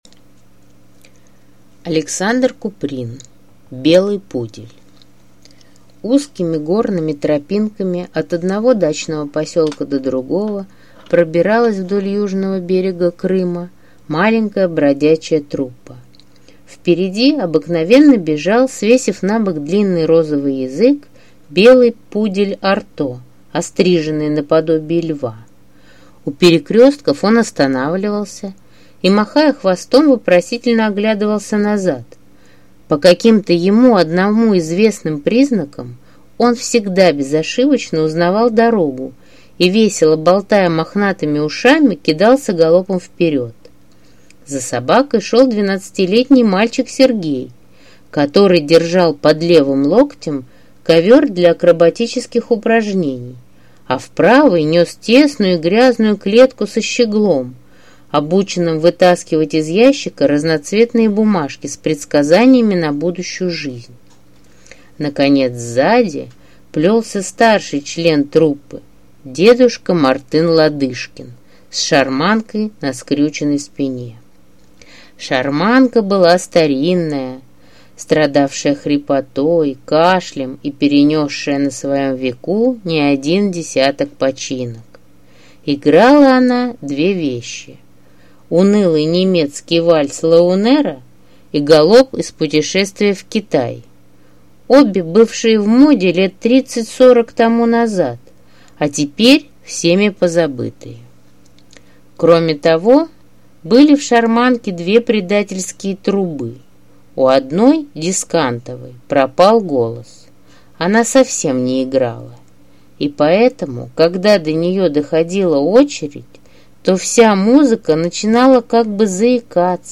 Аудиокнига Белый пудель | Библиотека аудиокниг